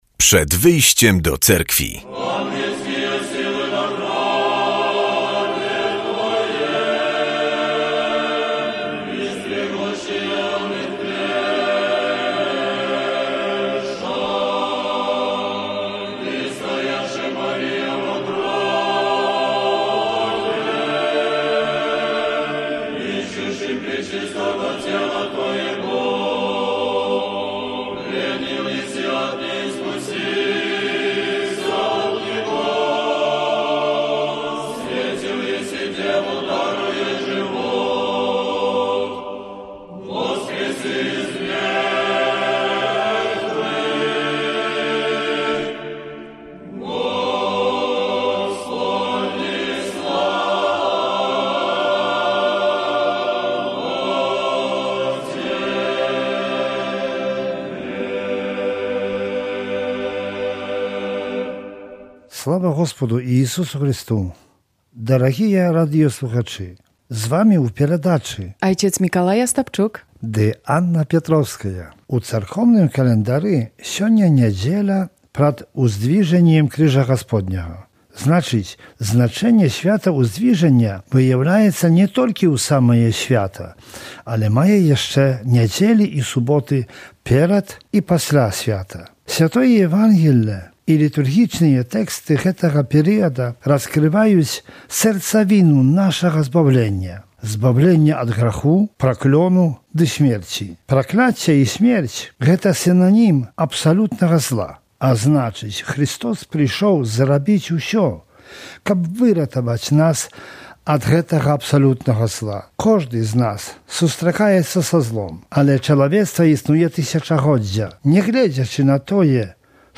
W audycji usłyszymy kazanie na temat niedzielnej Ewangelii i informacje z życia Cerkwi prawosławnej. Grono prawosławnych świętych powiększyli nowi męczennicy.